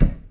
spell.ogg